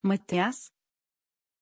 Pronunciación de Mattias
pronunciation-mattias-tr.mp3